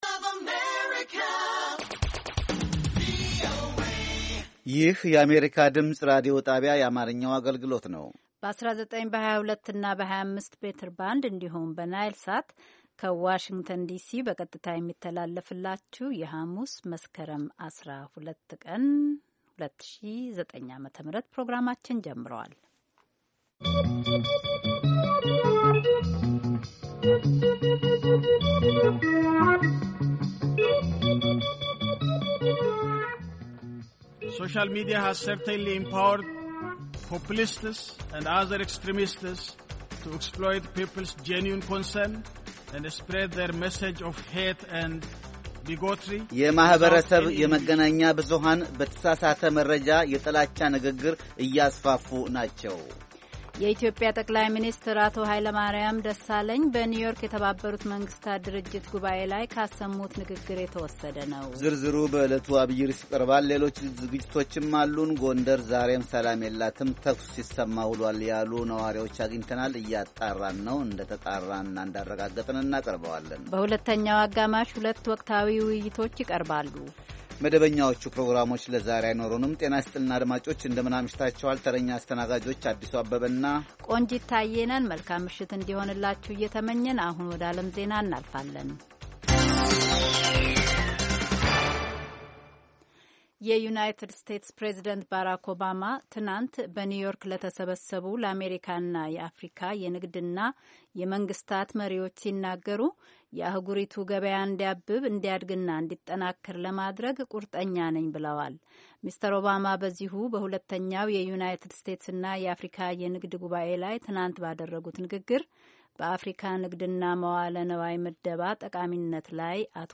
ቪኦኤ በየዕለቱ ከምሽቱ 3 ሰዓት በኢትዮጵያ ኣቆጣጠር ጀምሮ በአማርኛ፣ በአጭር ሞገድ 22፣ 25 እና 31 ሜትር ባንድ የ60 ደቂቃ ሥርጭቱ ዜና፣ አበይት ዜናዎች ትንታኔና ሌሎችም ወቅታዊ መረጃዎችን የያዙ ፕሮግራሞች ያስተላልፋል። ሐሙስ፡- ባሕልና ማኅበረሰብ፣ ሕይወት በቀበሌ፣ የተፈጥሮ አካባቢ፣ ሣይንስና ሕይወት